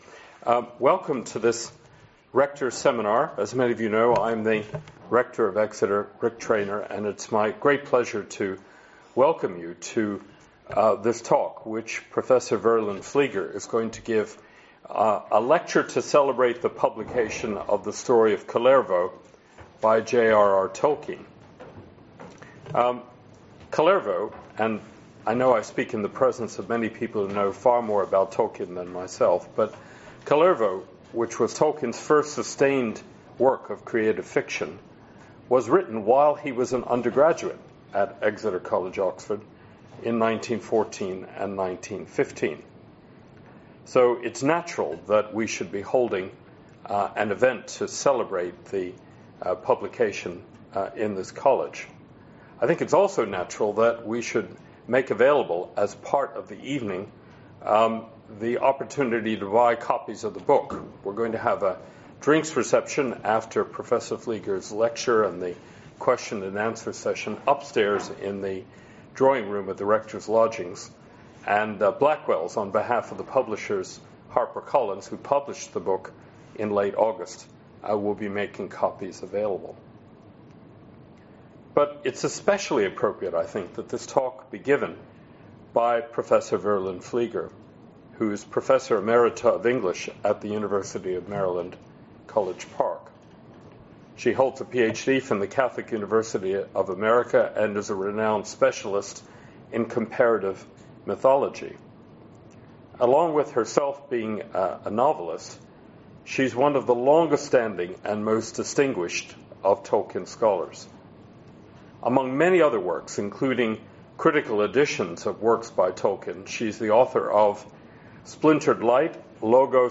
the_story_of_kullervo-a_lecture_by_verlyn_flieger.mp3